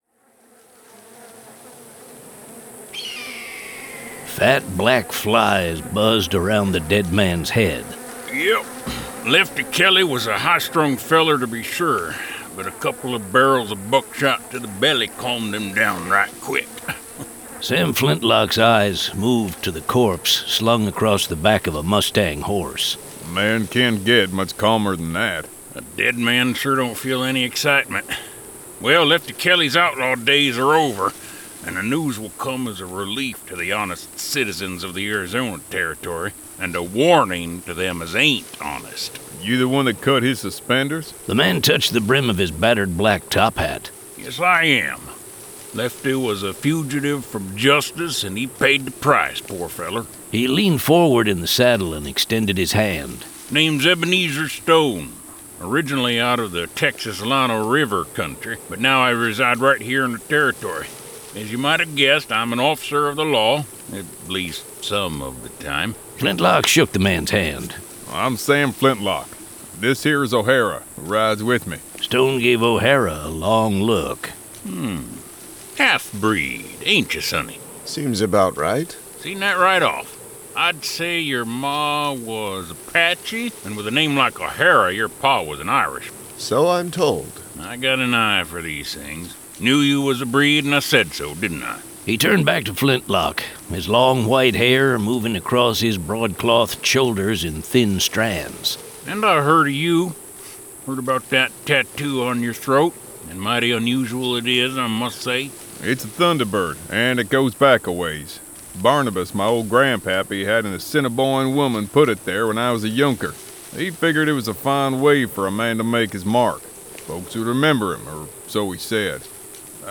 Flintlock 6: Pitchfork Pass [Dramatized Adaptation]